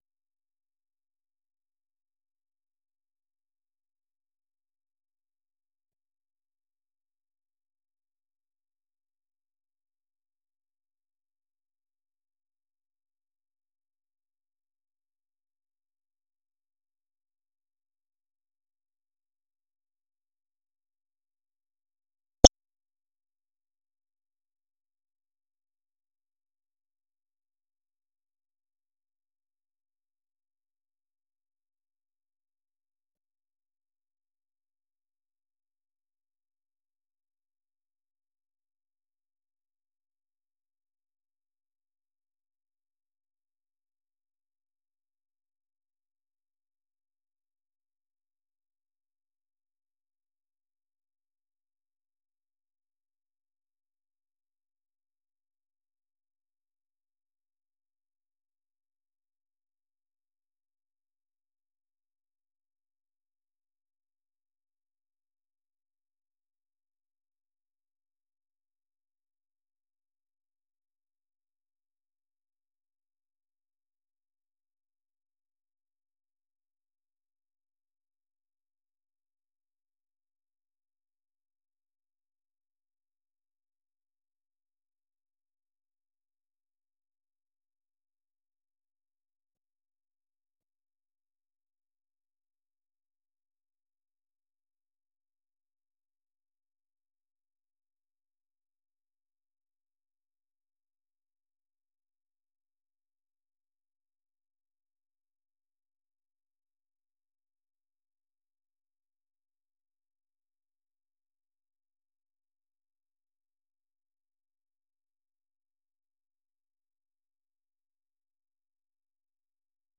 The URL has been copied to your clipboard 페이스북으로 공유하기 트위터로 공유하기 No media source currently available 0:00 0:59:57 0:00 생방송 여기는 워싱턴입니다 생방송 여기는 워싱턴입니다 아침 공유 생방송 여기는 워싱턴입니다 아침 share 세계 뉴스와 함께 미국의 모든 것을 소개하는 '생방송 여기는 워싱턴입니다', 아침 방송입니다.